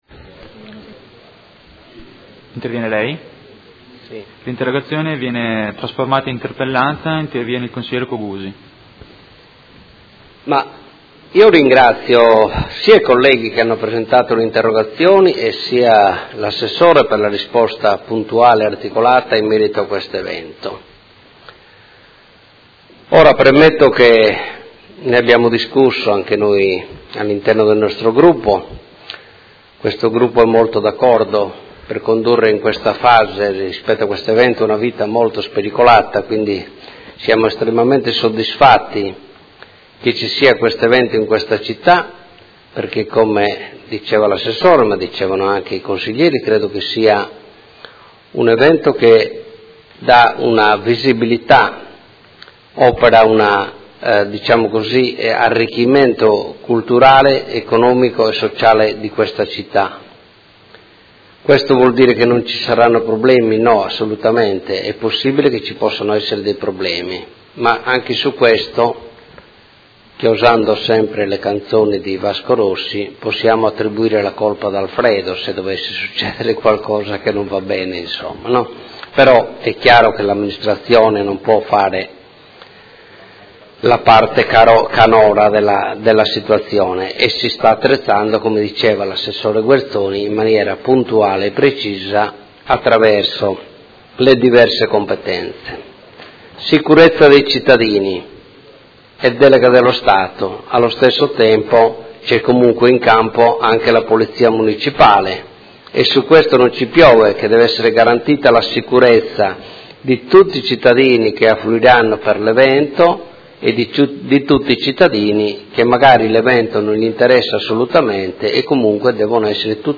Seduta del 30/03/2017. Chiede trasformazione in interpellanza delle interrogazioni sul concerto di Vasco Rossi e interviene per dibattito
Audio Consiglio Comunale